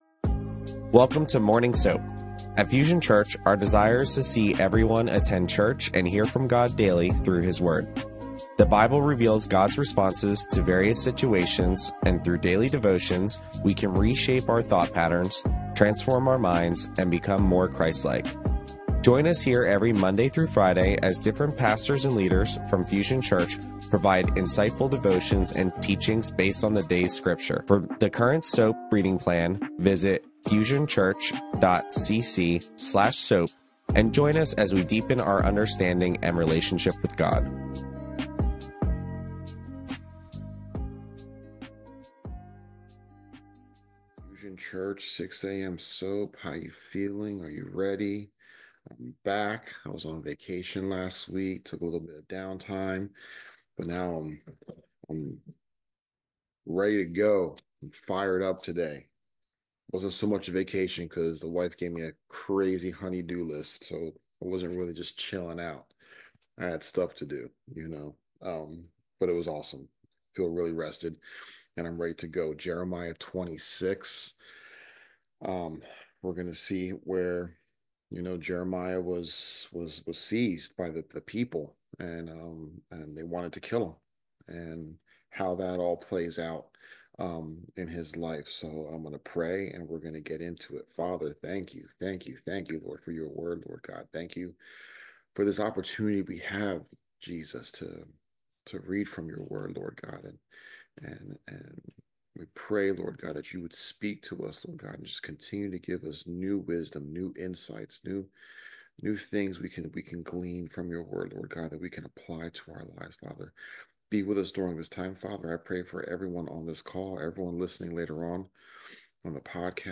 Fusion Church Morning SOAP Bible Study Podcast - Wednesday, July 24 | Jeremiah 26 | Free Listening on Podbean App